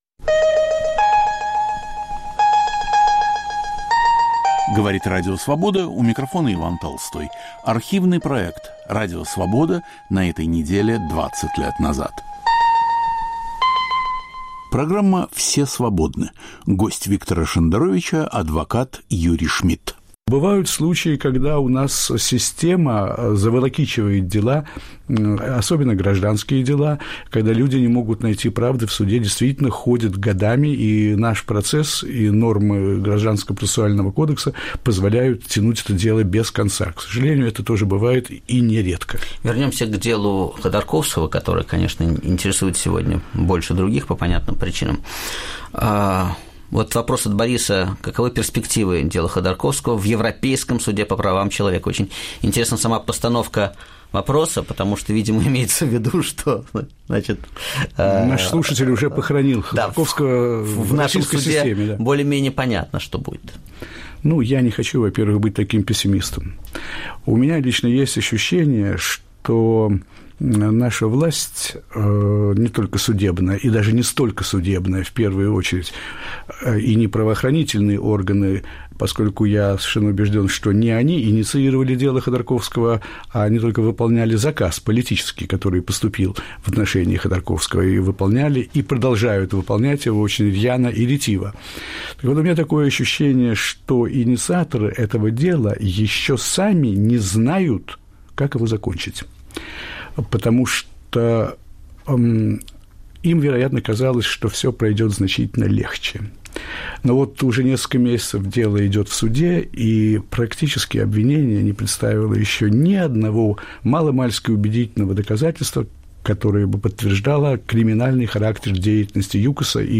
Все свободны. Гость Виктора Шендеровича адвокат Юрий Шмидт